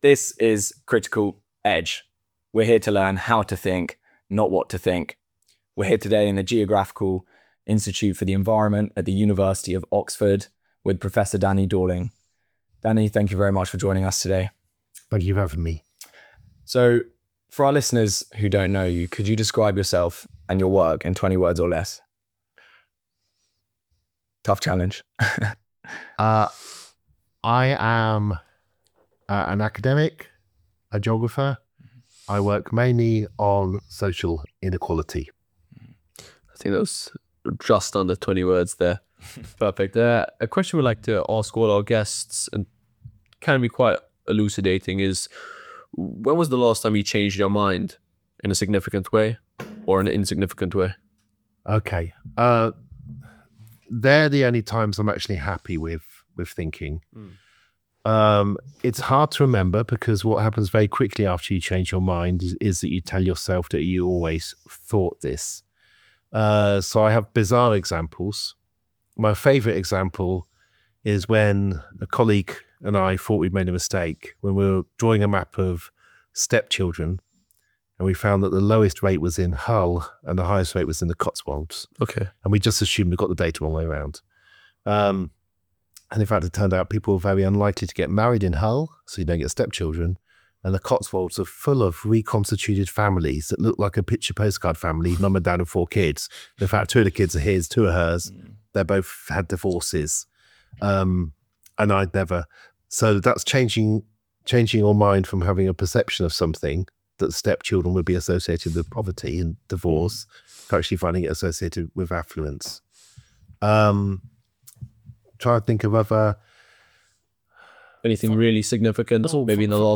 1 Cllr George Finch Debates Immigration | Critical Edge #54 1:00:16